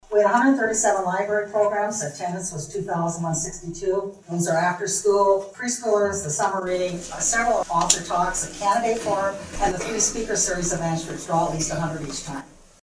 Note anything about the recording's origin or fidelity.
📖 The Lake View Public Library shared its annual report during the City of Lake View City Council meeting this past Monday.